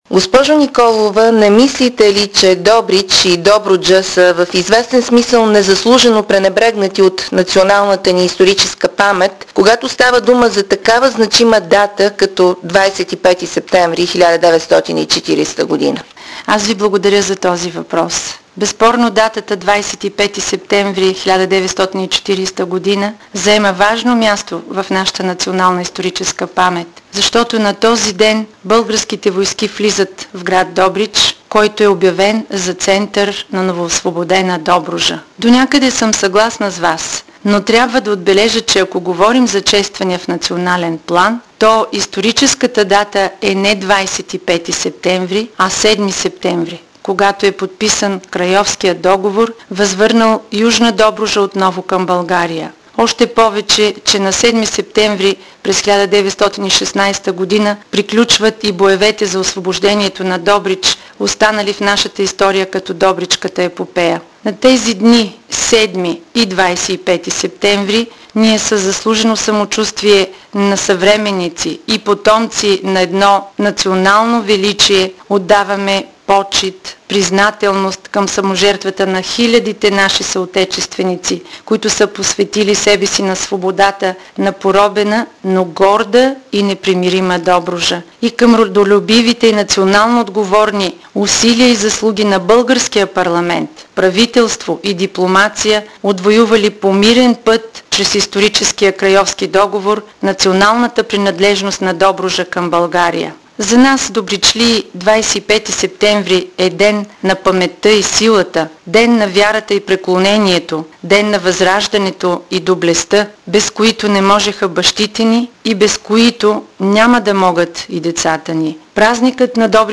По този повод в „Седмицата” ще чуем кмета на града Детелина Николова – за предстоящия празник на добричлии, за настоящето и бъдещето на общината.